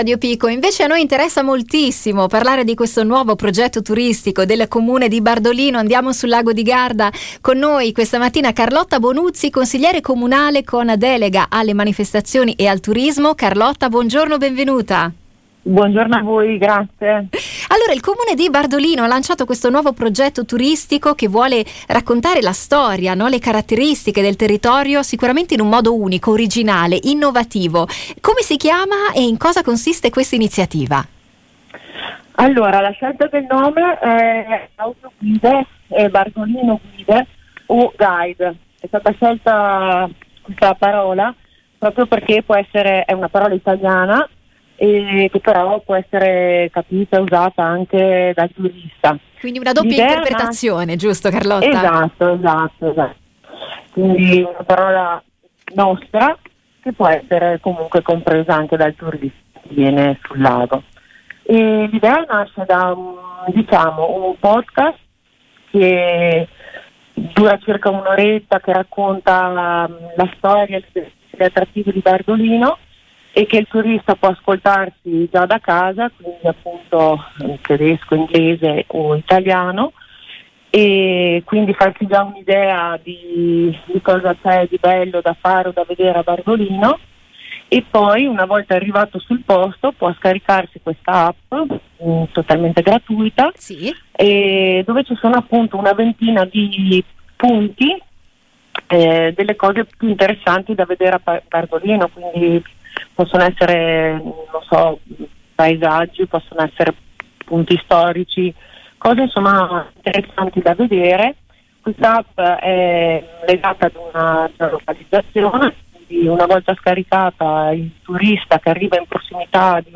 Ne abbiamo parlato con Carlotta Bonuzzi consigliere comunale con delega alle manifestazioni e al turismo del Comune di Bardolino: